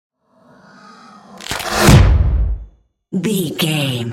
Dramatic whoosh to hit trailer
Sound Effects
Atonal
dark
intense
tension
woosh to hit